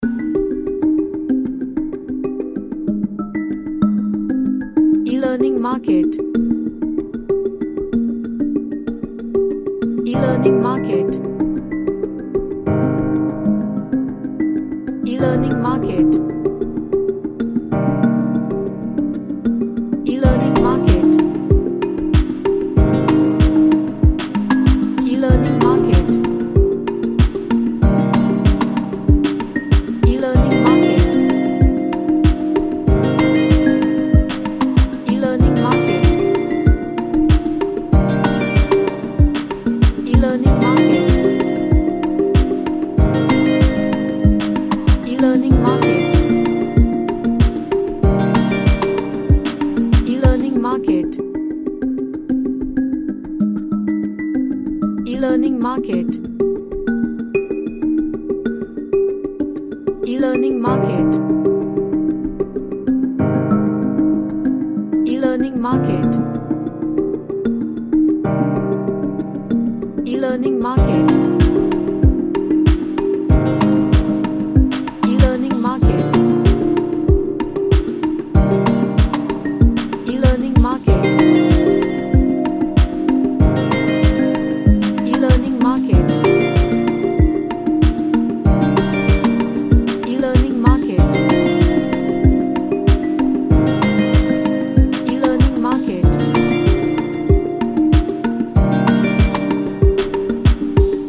An Ambient track with lots of arp and guitar melody.
Sad / Nostalgic